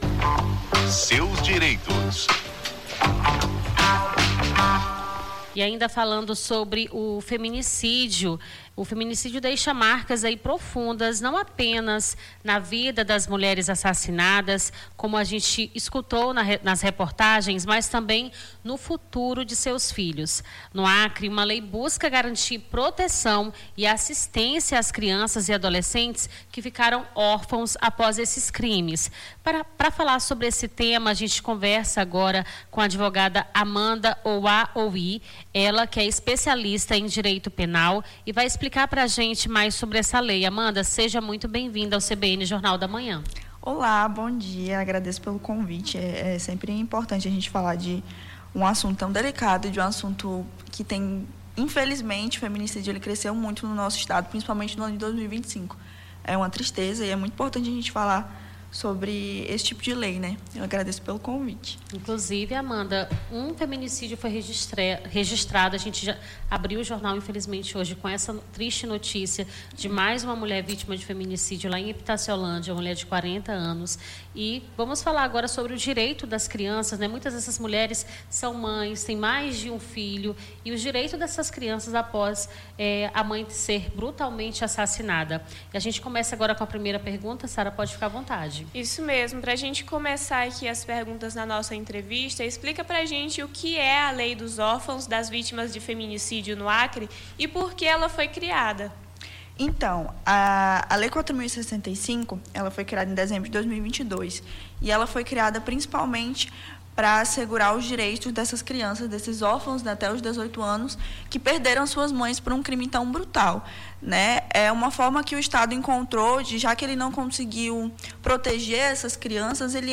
Seus Direitos: Advogada explica lei que assegura proteção e assistência a crianças e adolescentes órfãos de crimes - CBN Amazônia